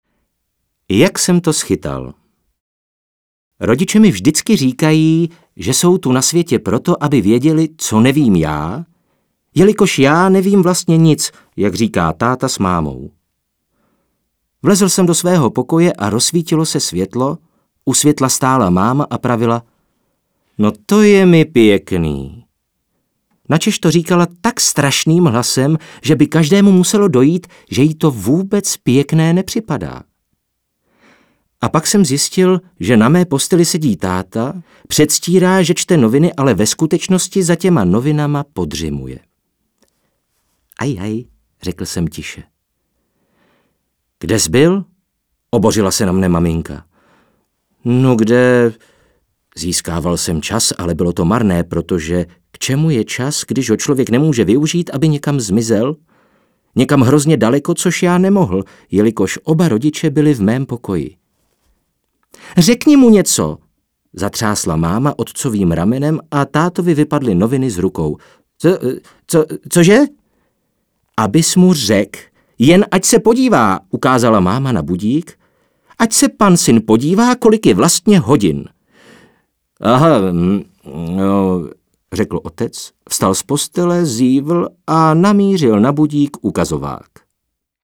Audioknihy: